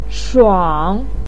shuang3.mp3